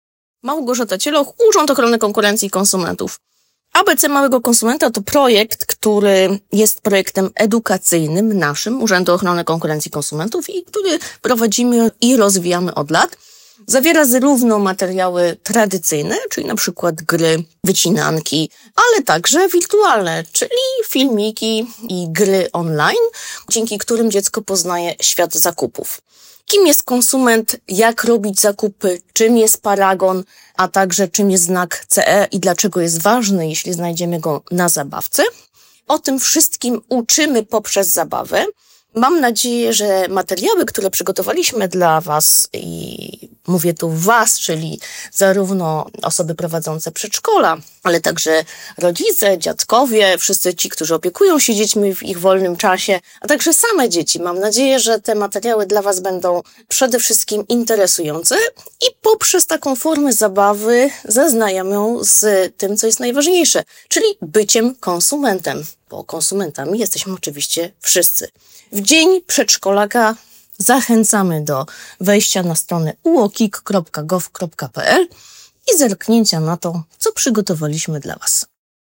Wypowiedź